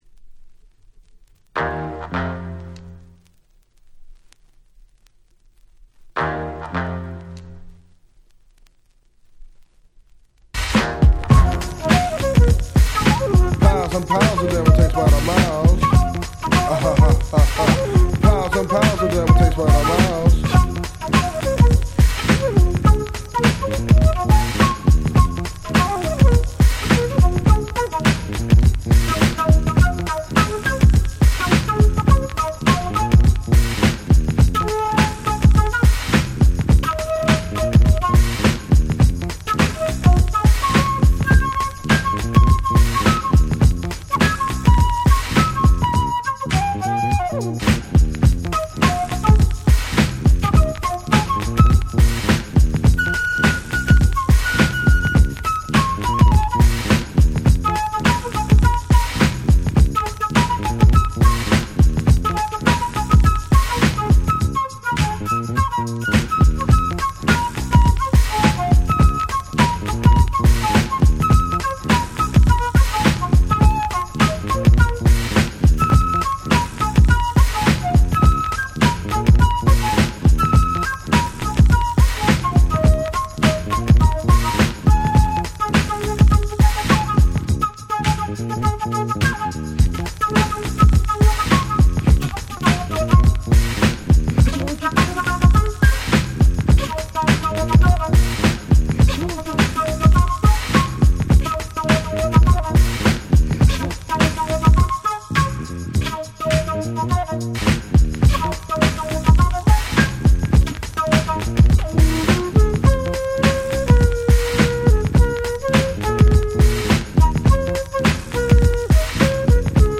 91' Smash Hit Hip Hop !!
Boom Bap ブーンバップ